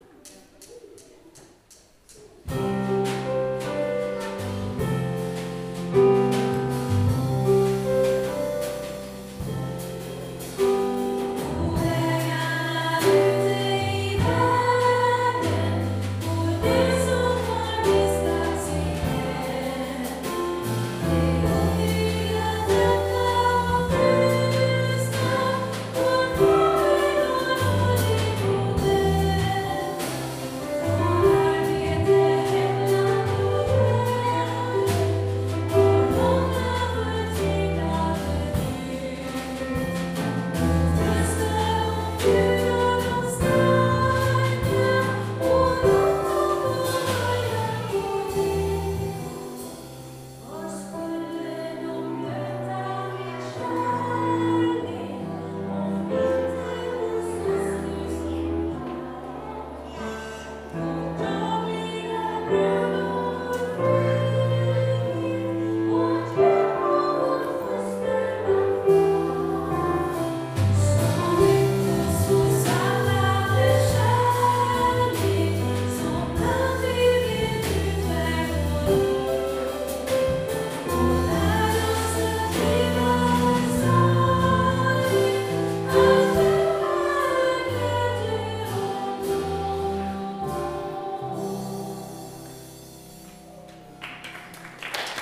Jag har tonsatt texter av bl.a. Pernilla Carli, Britt G Hallqvist och ord från Bibeln som jag gett ut i ett sånghäfte för barnkör som heter Välkommen hit!
från en vårkonsert maj 2019
piano
trummor
cello